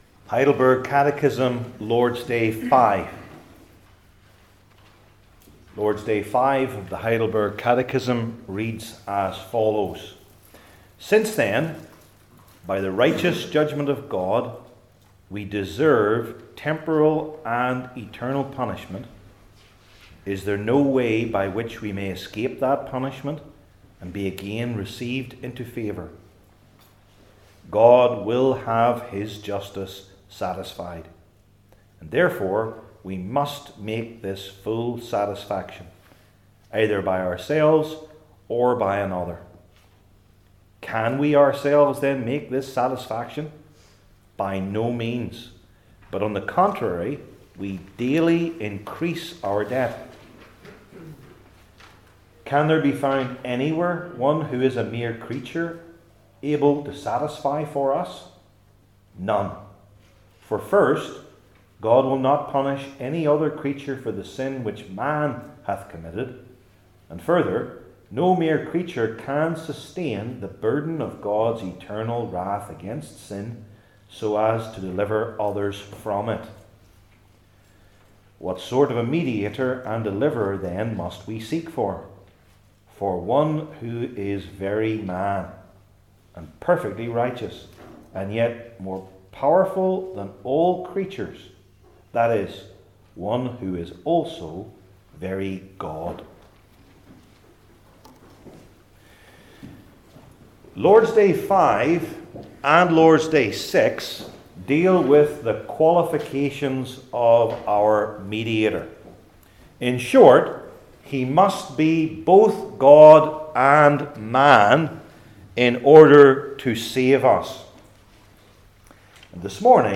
Romans 8:1-18 Service Type: Heidelberg Catechism Sermons I. What It Is II.